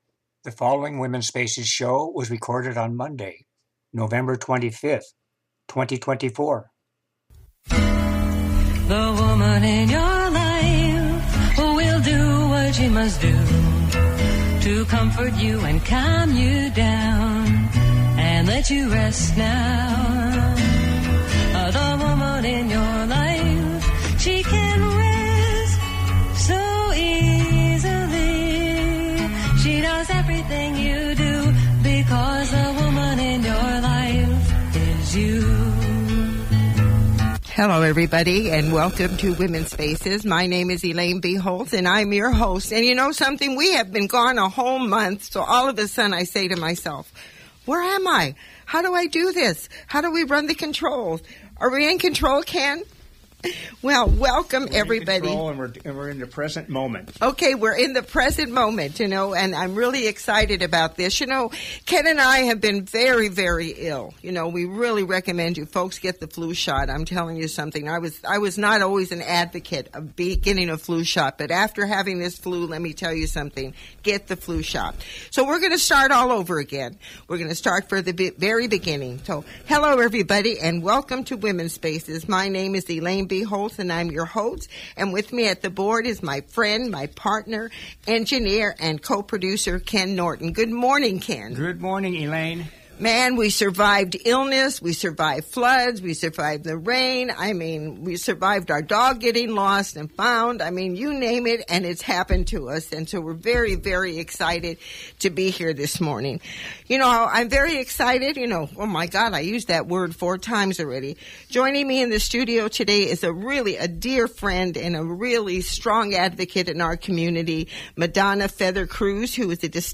After a four week absence, we are back with a live show.